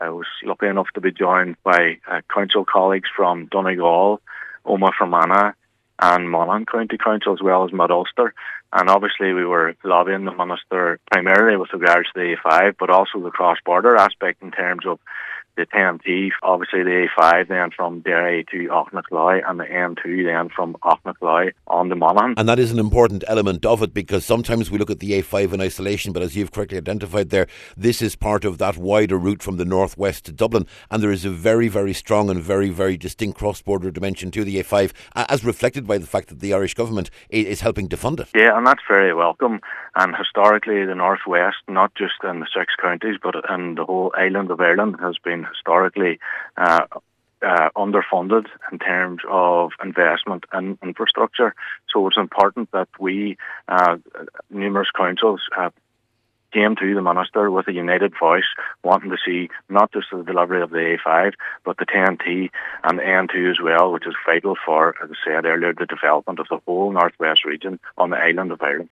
Mayor of Derry City and Strabane Cllr Ruari McHugh says the meeting was an important one, because it also discussed the importance of the Ten-T from Letterkenny to Lifford and on to the border, and the N2 on the Monaghan side of Aughnacloy.
Cllr McHugh says the cross border significance of the A5 cannot be overstated……..